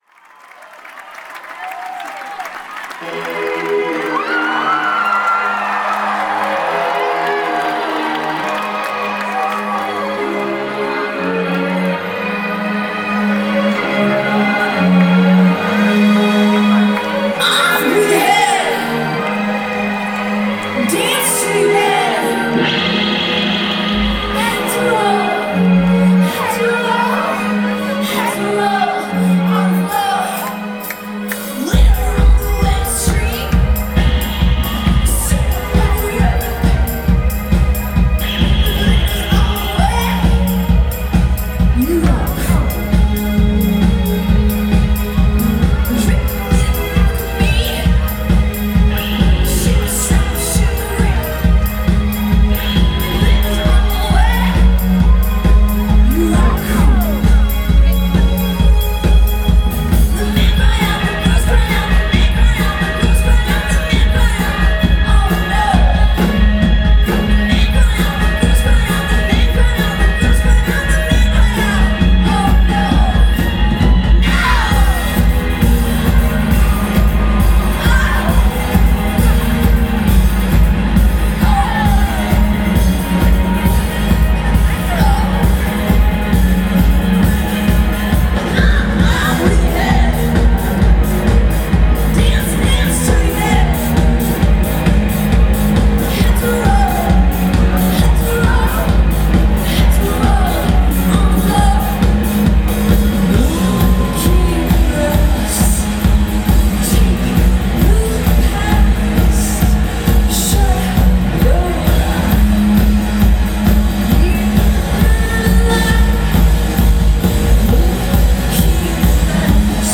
This is a live track from the 2009 show at Radio City.